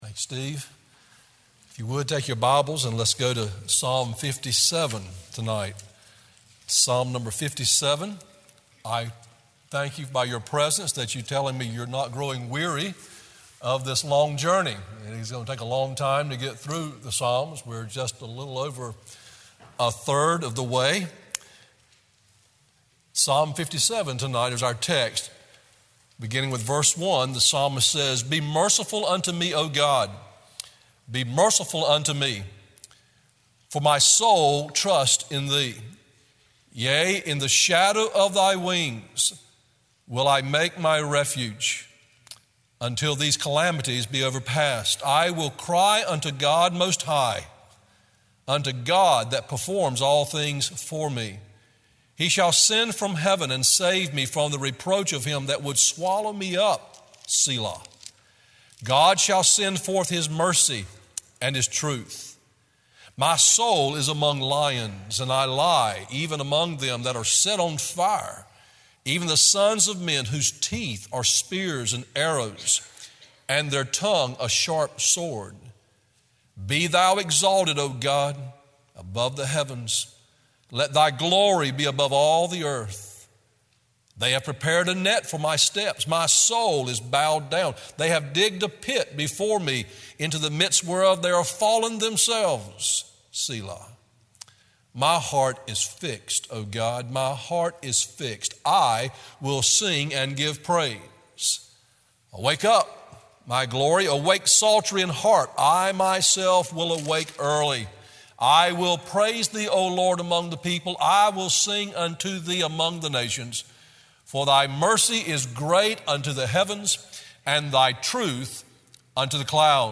Sermon Audios/Videos - Tar Landing Baptist Church